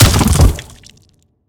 biter-death-big-3.ogg